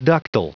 Prononciation du mot ductile en anglais (fichier audio)
Prononciation du mot : ductile